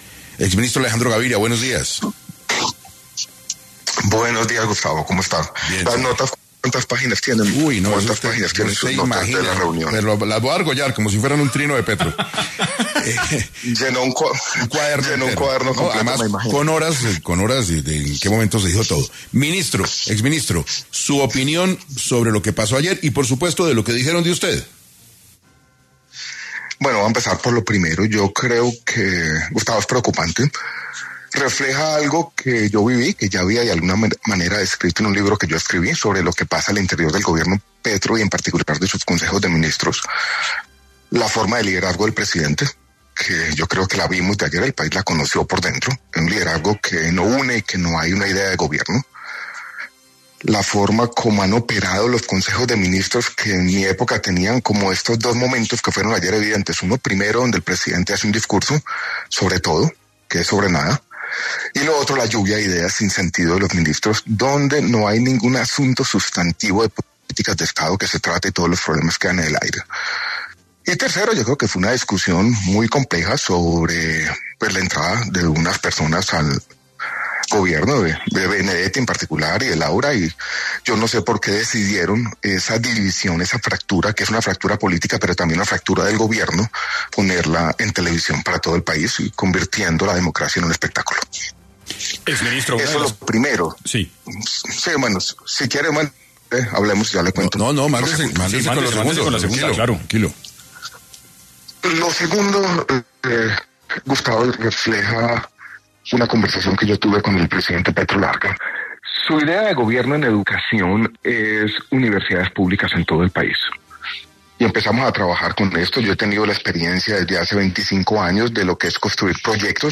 En entrevista para 6AM, Alejandro Gaviria analizó la situación por la que atraviesa el gobierno y respondió a las críticas presentadas por Gustavo Petro.